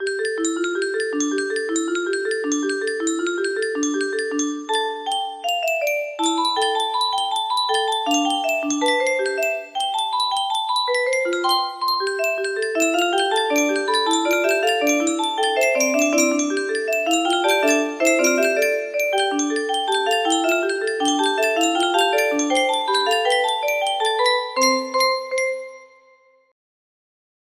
Cancioncita Original V1 music box melody